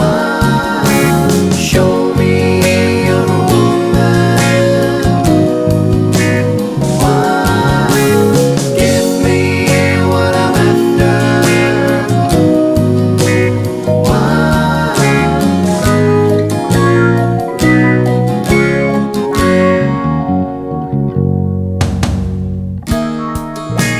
Medley Glam Rock 3:11 Buy £1.50